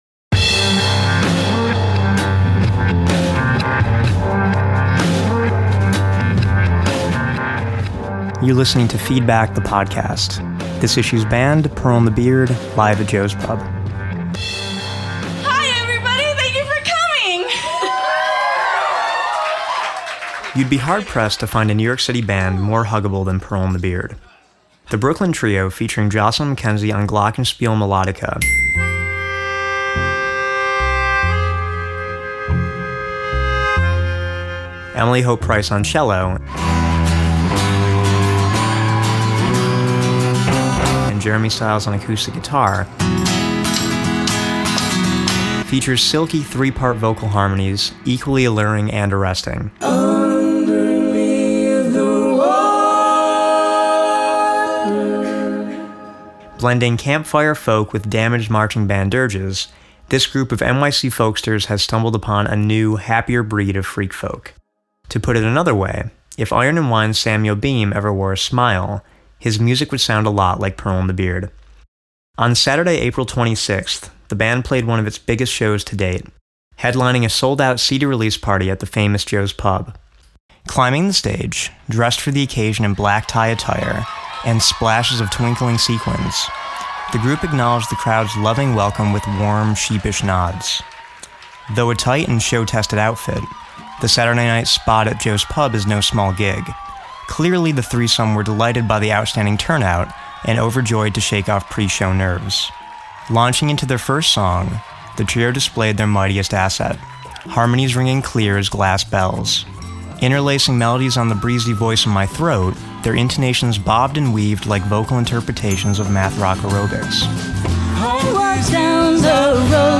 pearl-the-beard-podcast-masteredmp3.mp3